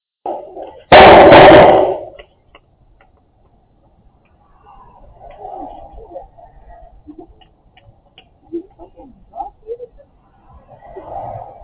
The cam did capture audio, however, and combined with ‘compatible’ stock footage, I’ve created a pseudo-event.
Here’s the sound of someone in So Cal misreading a stop sign - and knocking it down.
Yeah, it was a pretty good WHAM! 40 ft from a hypersensitive v2 mic (it sometimes captures my thoughts if I think too loud. :grin: )